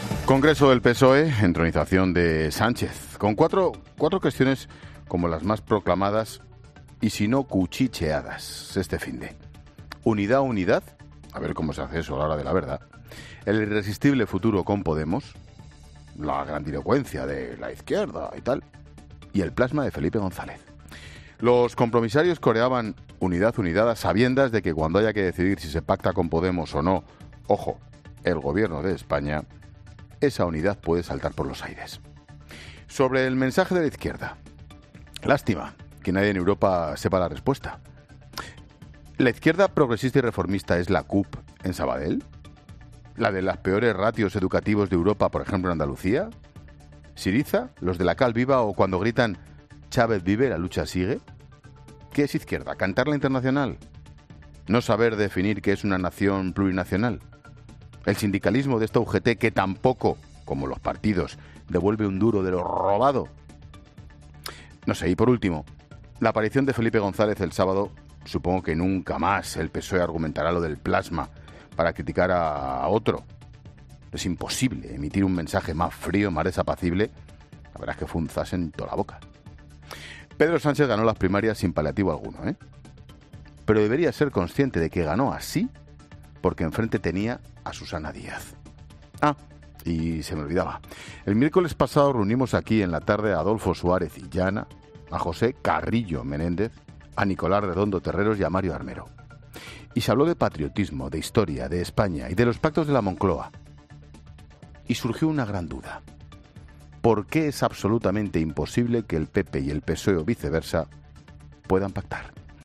AUDIO: Monólogo de Ángel Expósito a las 18h. con las claves del Congreso Federal del PSOE.